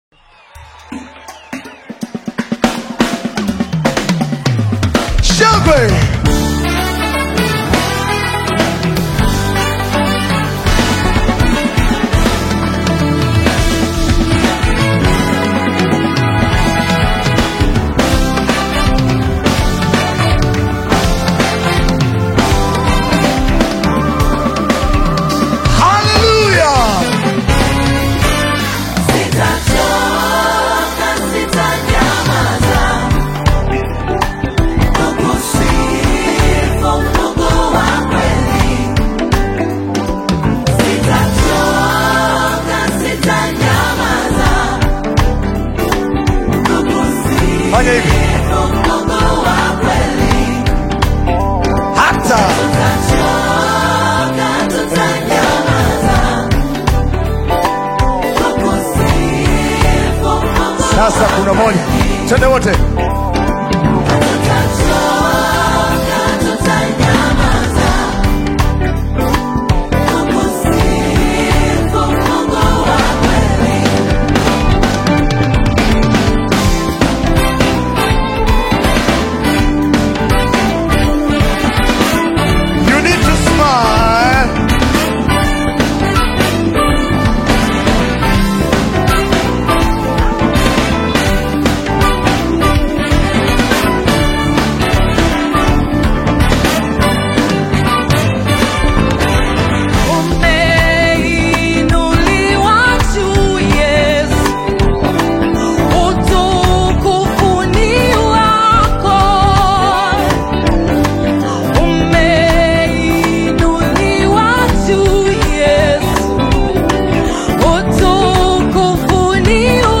gospel group
praise and worship song